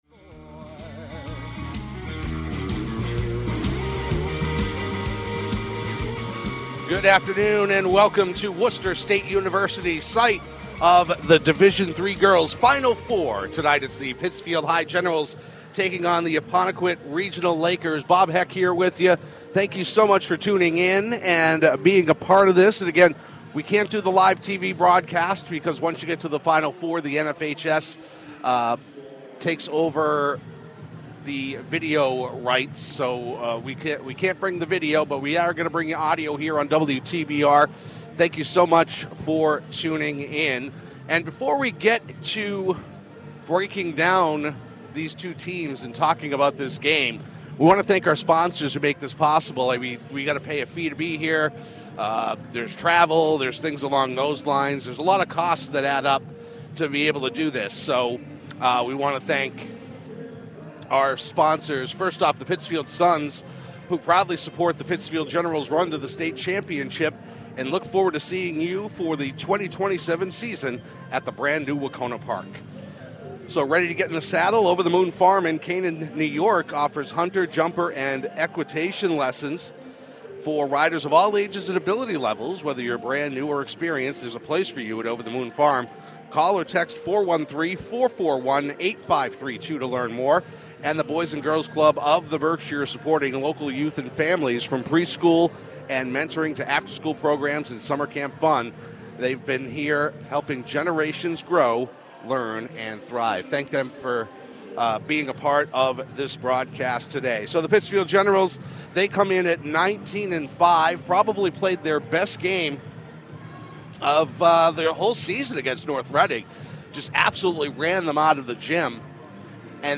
Listen to the full broadcast as the Apponequet Regional Lakers take on the Pittsfield High Generals at Worcester State University.